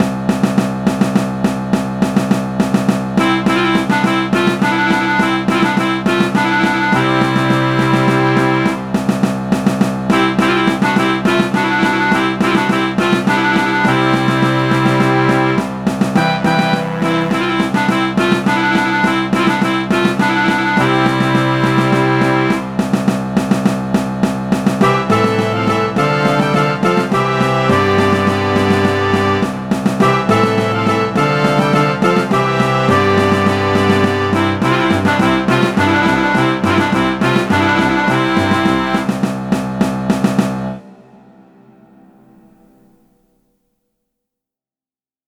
(In orchestration. No video.)